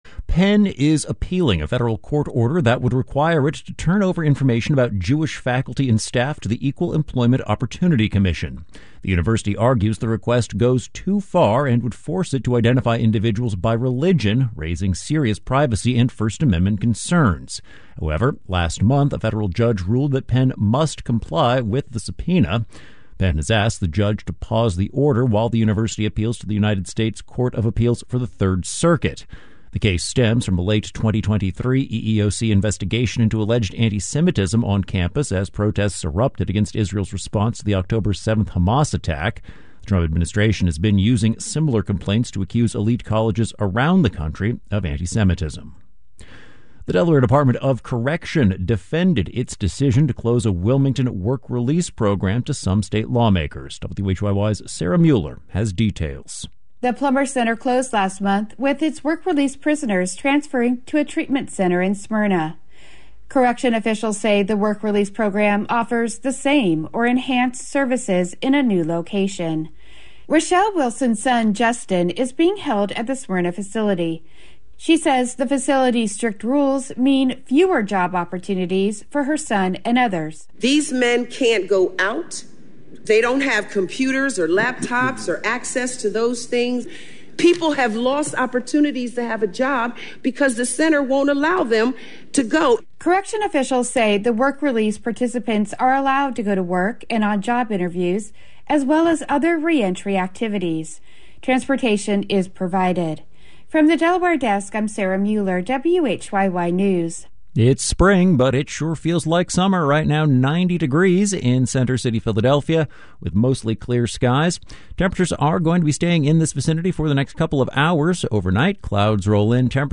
WHYY Newscast